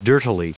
Prononciation du mot dirtily en anglais (fichier audio)
Prononciation du mot : dirtily
dirtily.wav